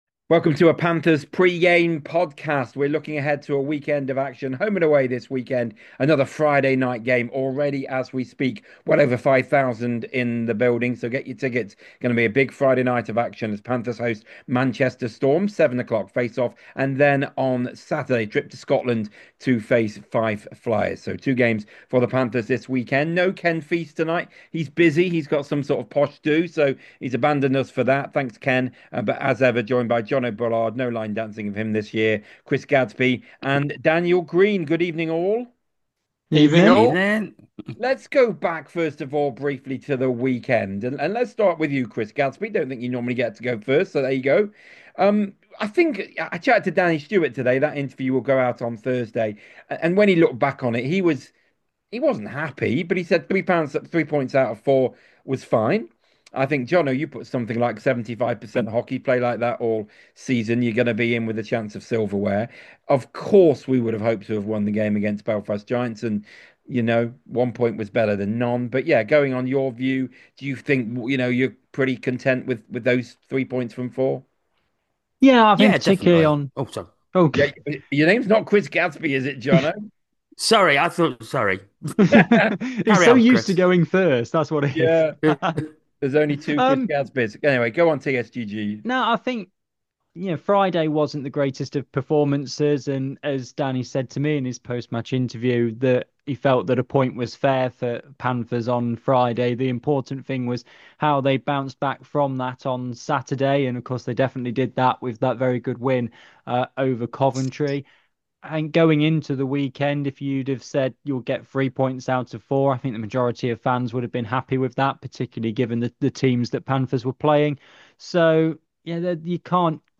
The four guys also discuss Ollie Betteridge being named GB Captain for this week's IIHF European Cup of Nations tournament and touch on the permutations for who Panthers might face in the Elite League Elimination Game.